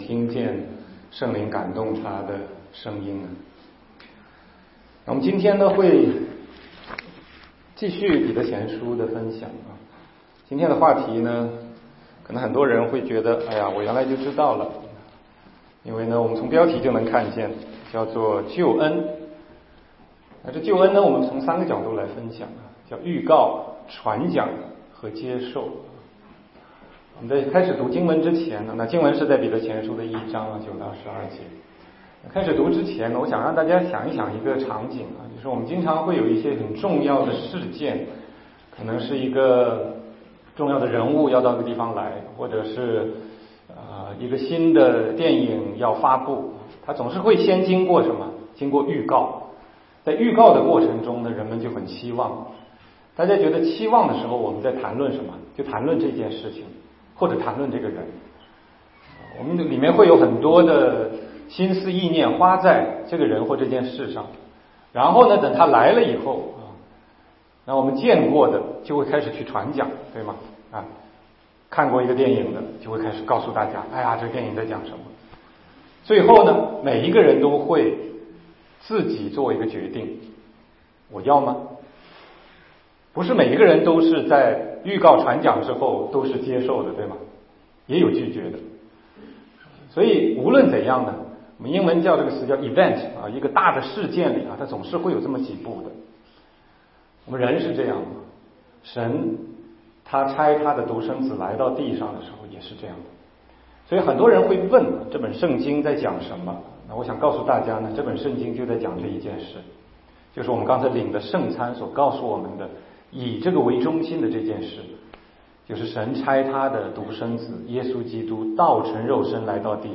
全中文讲道与查经